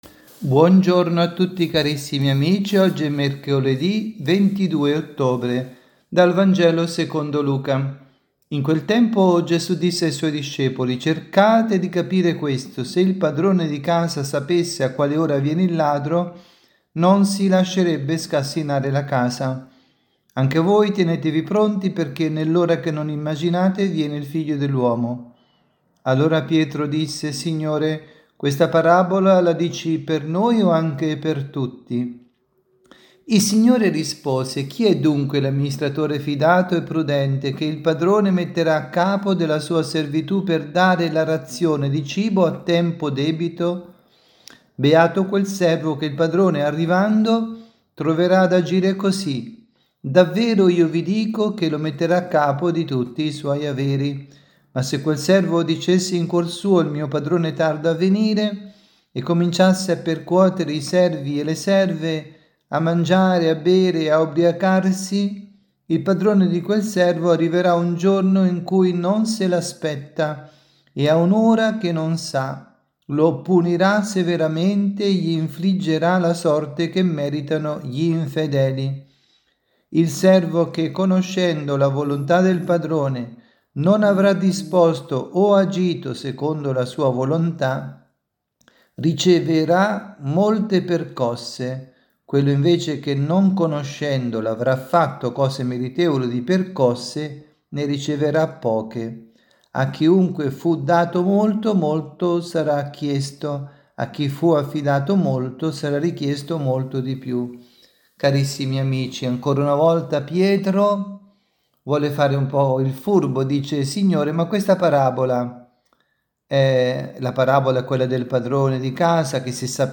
Catechesi
dalla Basilica di San Nicola – Tolentino